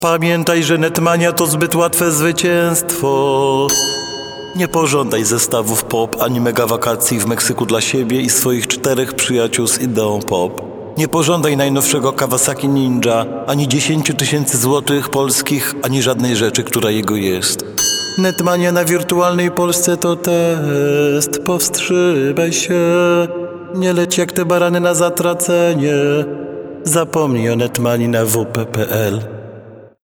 Sam oceń reklamę radiową